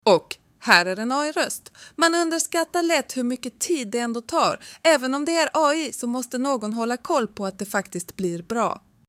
Här kan du lyssna på inspelad människoröst, klonad röst och AI-röst